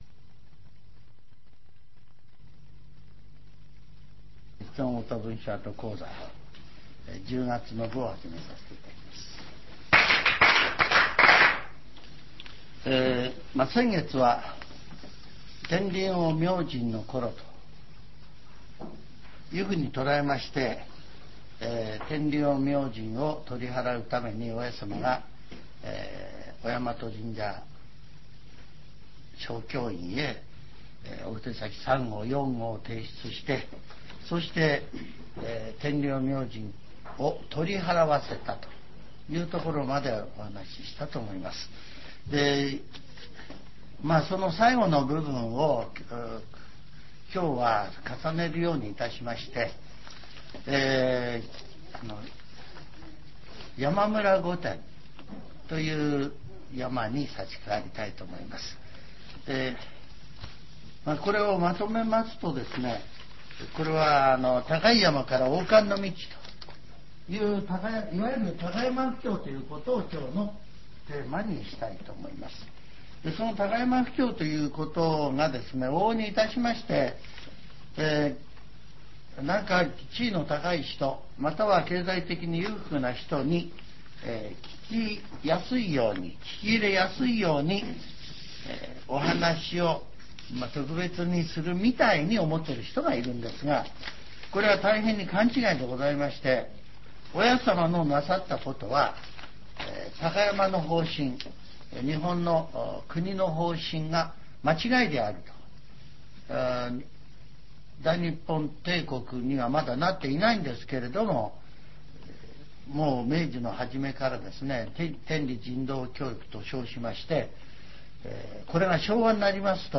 全70曲中26曲目 ジャンル: Speech